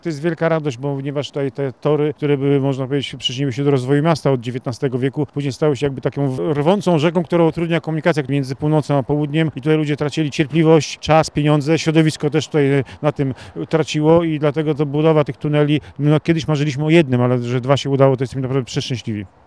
Dzięki nowemu tunelowi można sprawnie dotrzeć w każdy zakątek Sulejówka – mówi burmistrz Arkadiusz Śliwa.